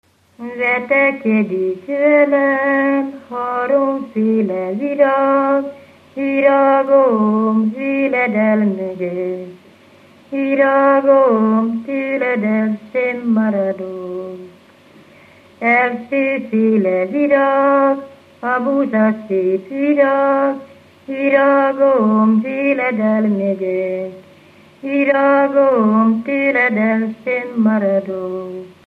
Felföld - Nyitra vm. - Ghymes
Műfaj: Szentiváni ének
Gyűjtő: Vikár László
Stílus: 5. Rákóczi dallamkör és fríg környezete